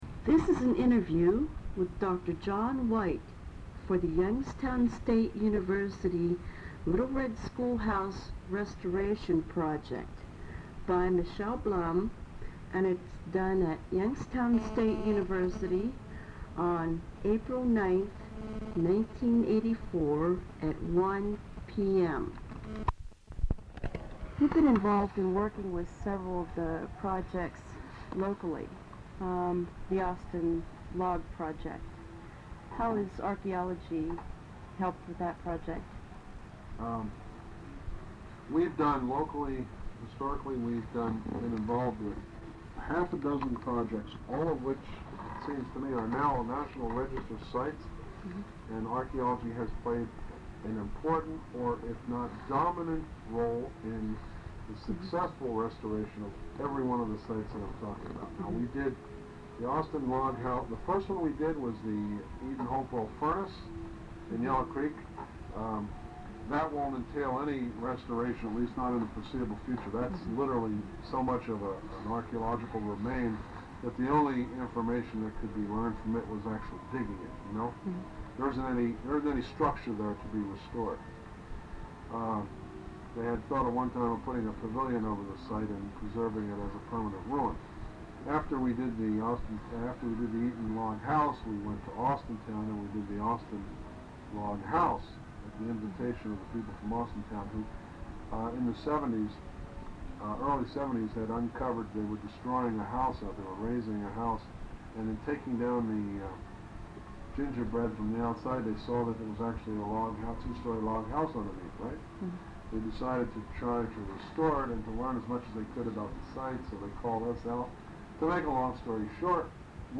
Transcript of interview taped on April 9, 1994.
Oral Histories